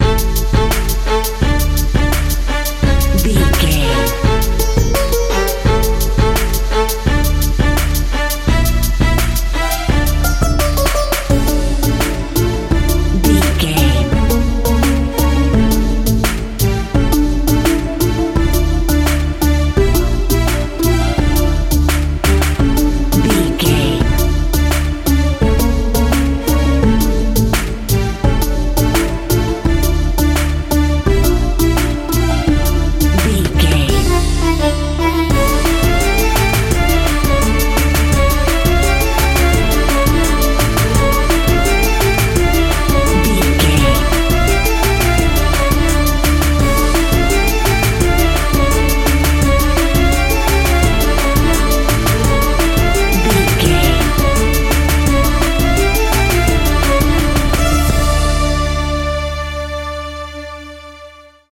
Aeolian/Minor
Slow
World Music
percussion